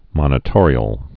(mŏnĭ-tôrē-əl)